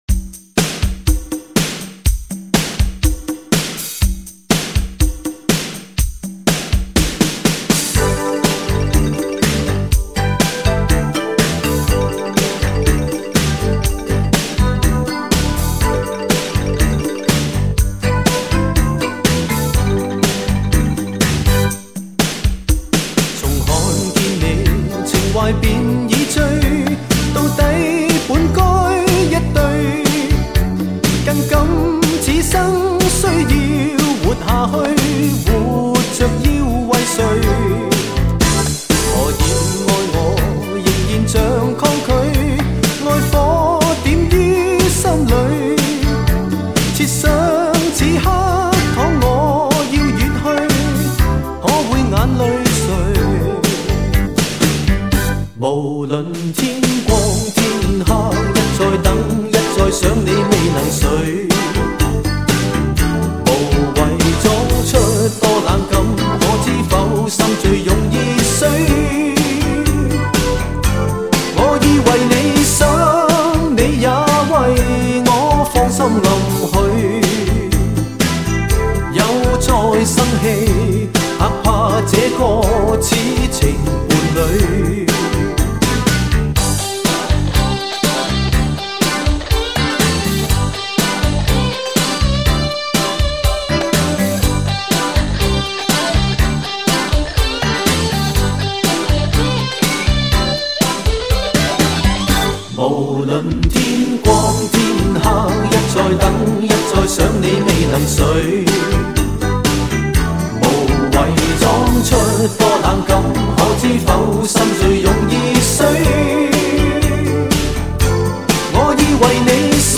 无损音乐
极致试听发烧靓声
高密度24bit数码录音